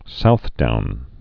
(southdoun)